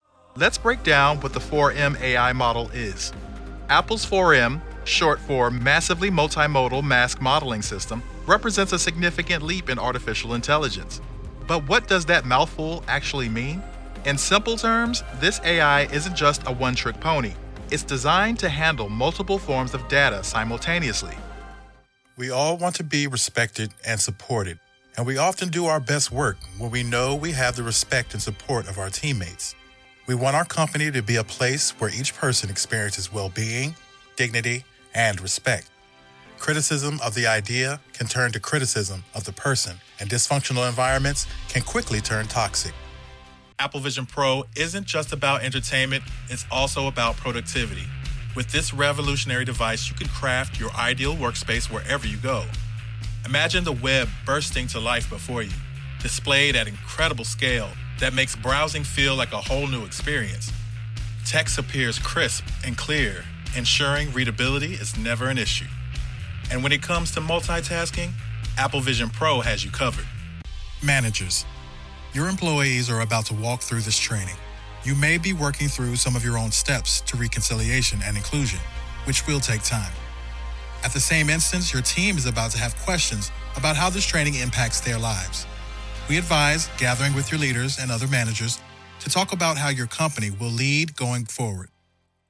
Commercial Demo
I record in my home studio (RODE Podmic & Ebxya interface) and send the files to you in the format of your choice.